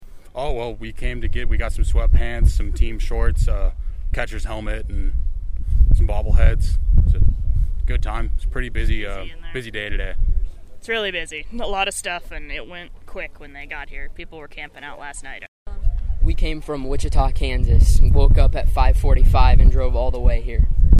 Fans came from all around, and KMAN spoke to some.